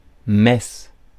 Ääntäminen
Synonyymit liturgie Ääntäminen France: IPA: [mɛs] Haettu sana löytyi näillä lähdekielillä: ranska Käännös Konteksti Substantiivit 1. misa {f} kristinusko Suku: f .